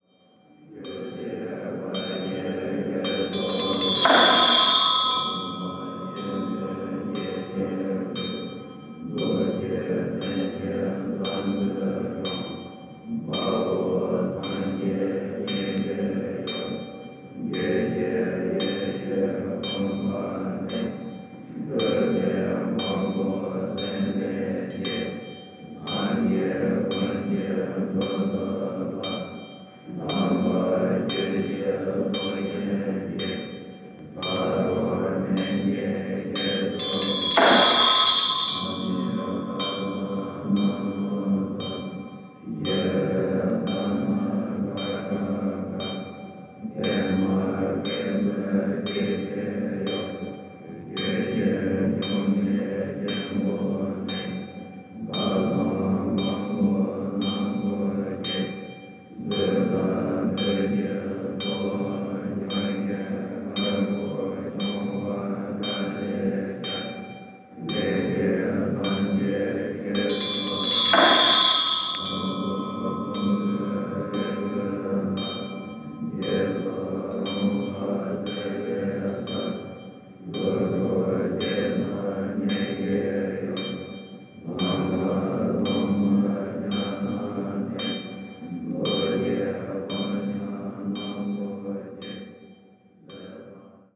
Title : Tibetan Buddhism: The Ritual Orchestra And Chants
チベット幻覚系・フィールド録音盤！！！